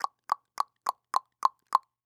Tongue Clicks Sound
human
Tongue Clicks